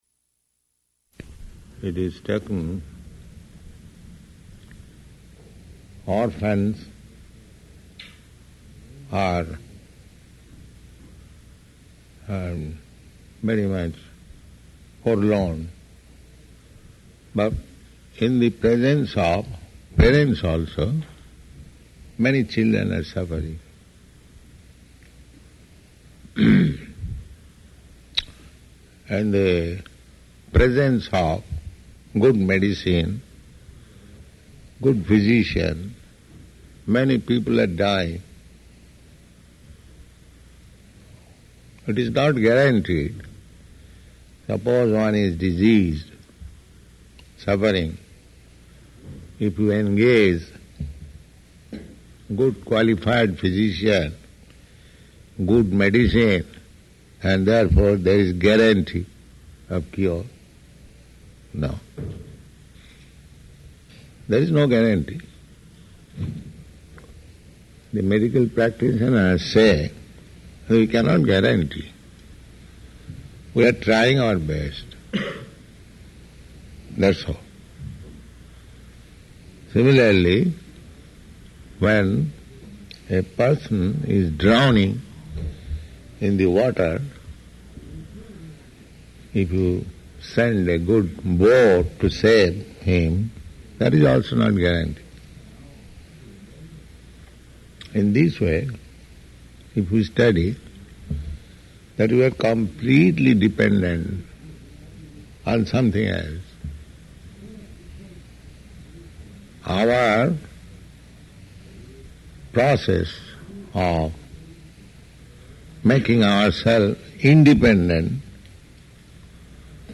Lecture [partially recorded]